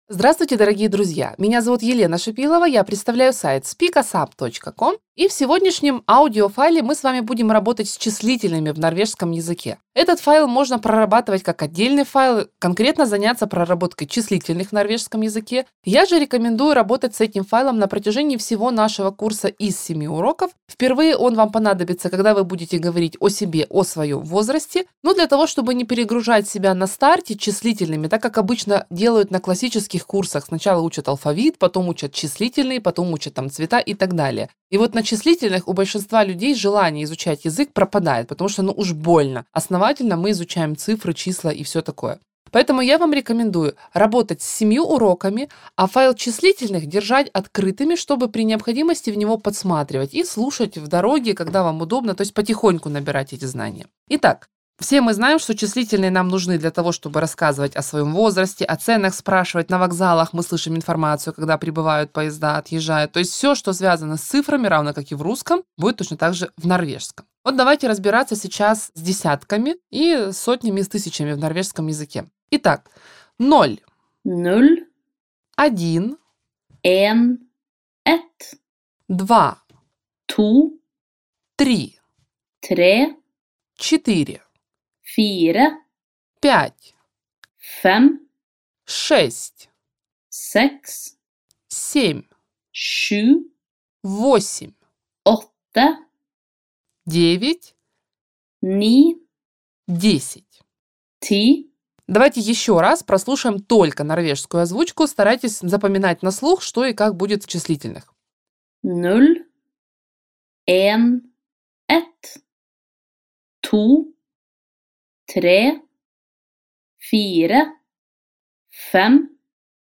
  Прослушайте аудио урок с дополнительными объяснениями